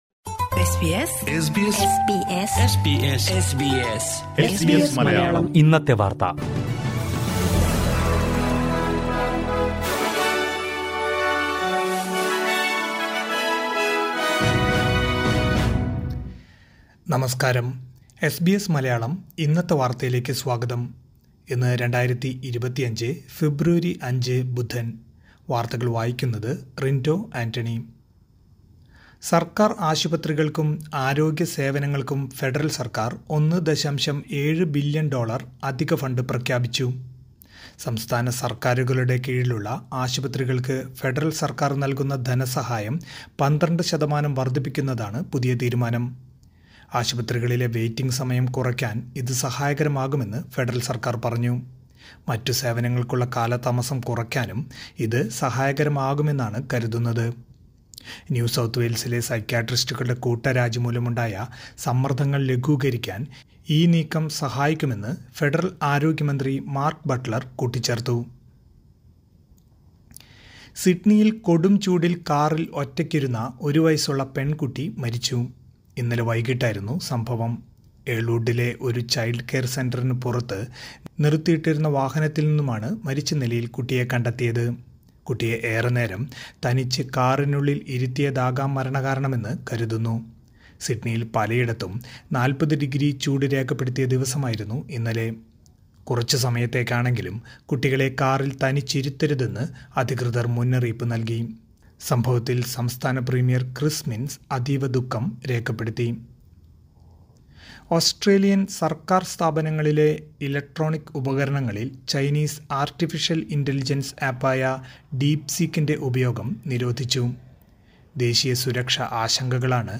2025 ഫെബ്രുവരി അഞ്ചിലെ ഓസ്‌ട്രേലിയയിലെ ഏറ്റവും പ്രധാന വാര്‍ത്തകള്‍ കേള്‍ക്കാം...